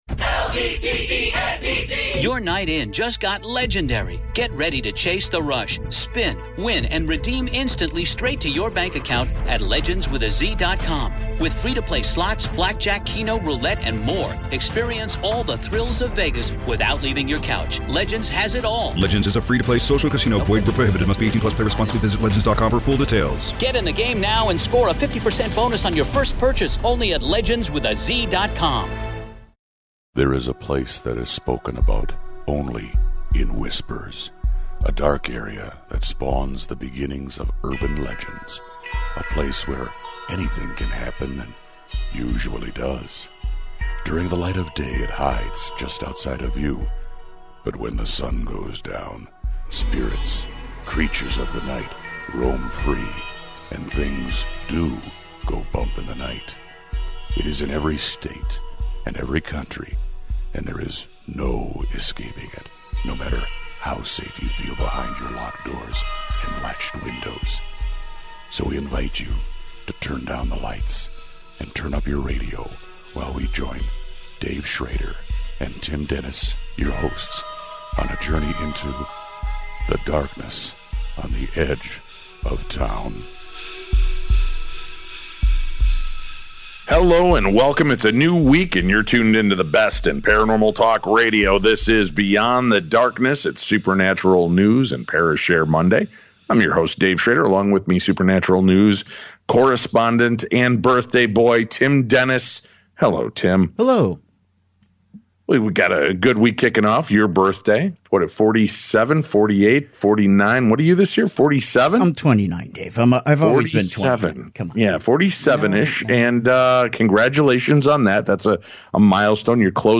Society & Culture, Hobbies, Leisure, Religion & Spirituality, Spirituality, Philosophy